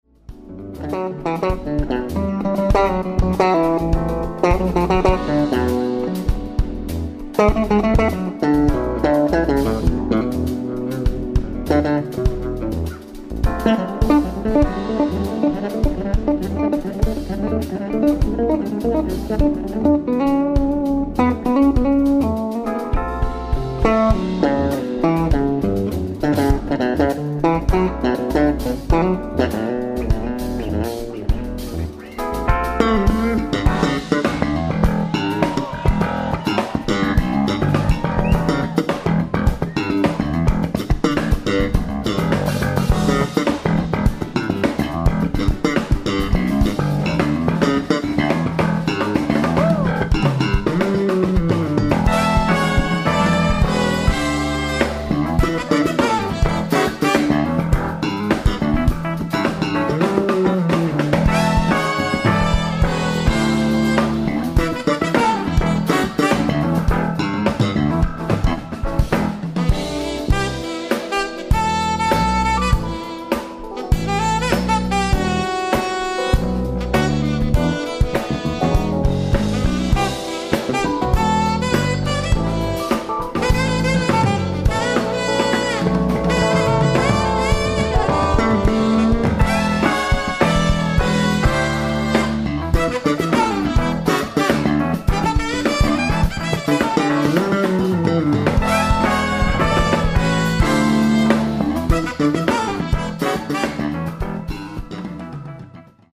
オフィシャル級クオリティー！！
※試聴用に実際より音質を落としています。